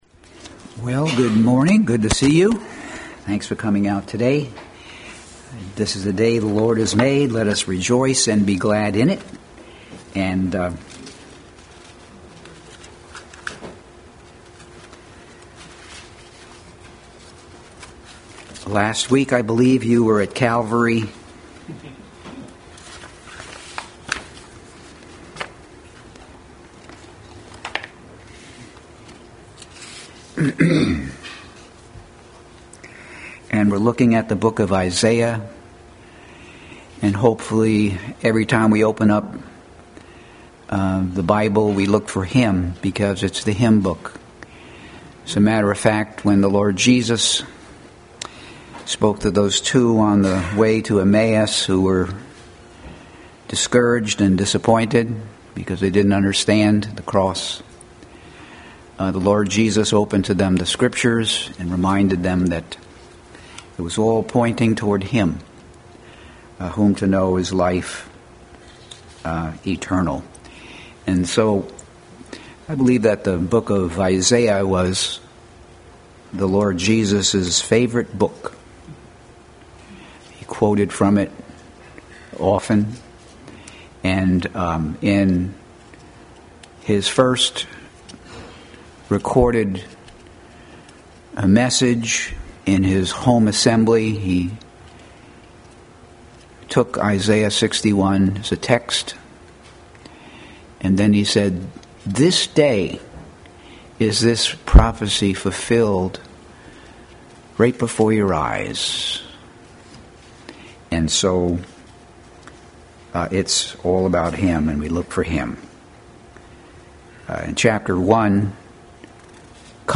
Adult Sunday School Class continued study of Christ in Isaiah.